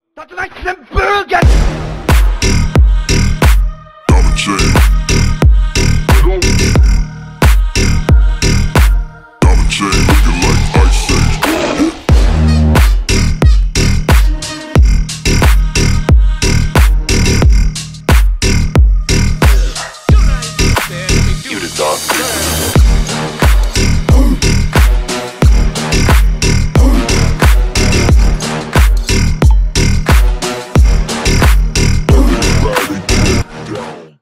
громкие
клубные